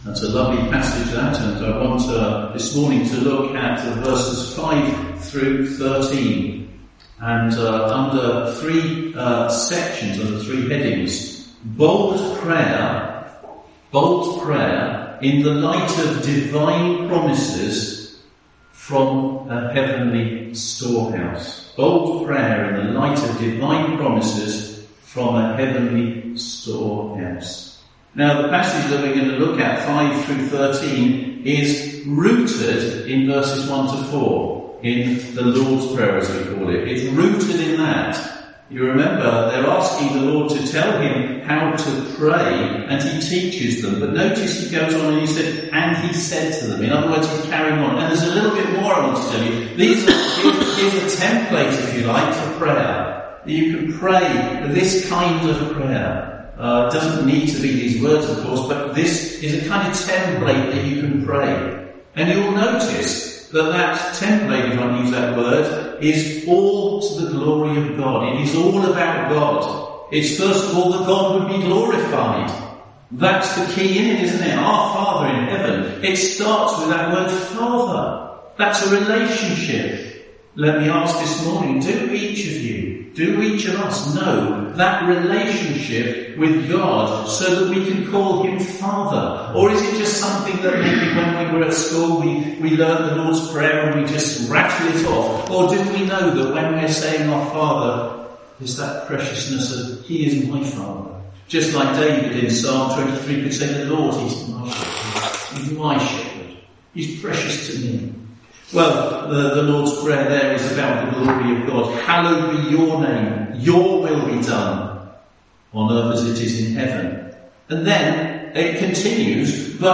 One-off sermons